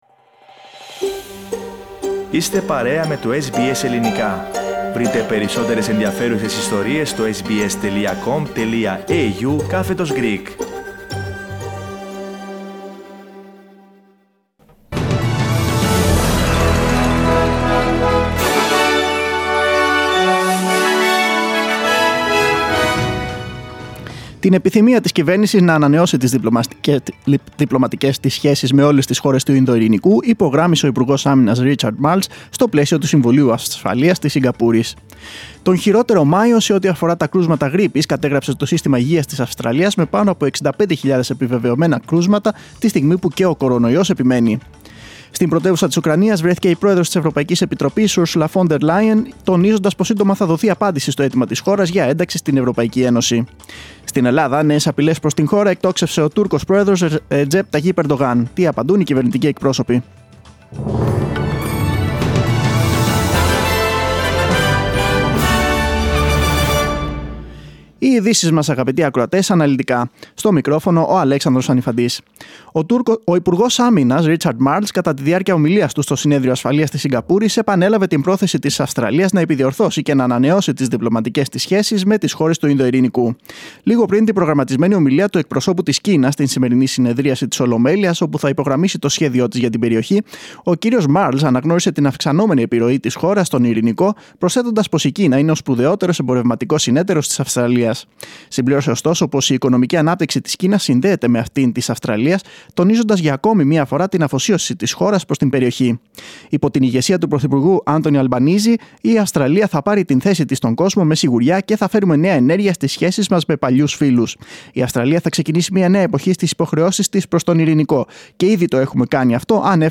Δελτίο Ειδήσεων 12.6.2022